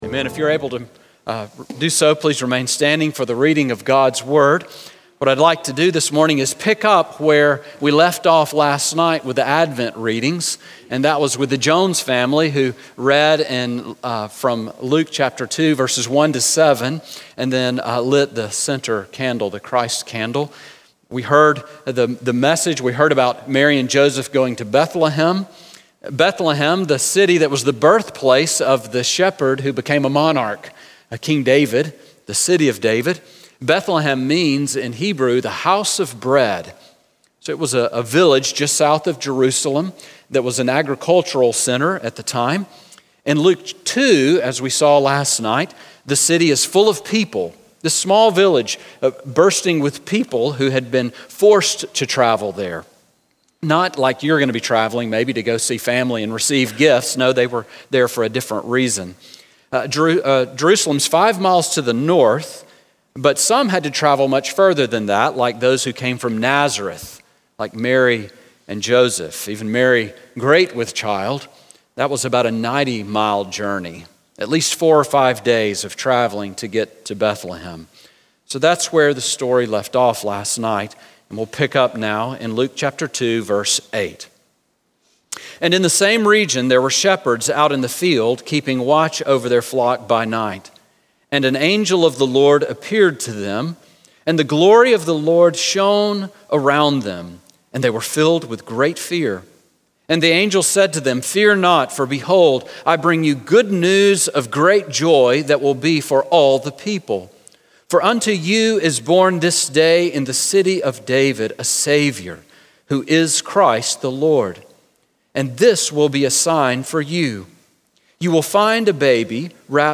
« Back to sermons page Unexpected Evangelists Sermon from December 25